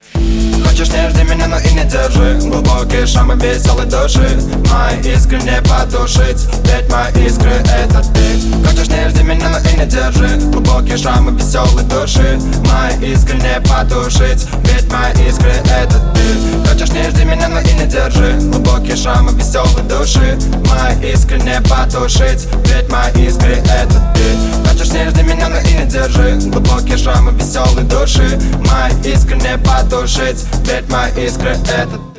• Качество: 128, Stereo
ритмичные
Хип-хоп
душевные
Любовный рингтон, очень душевный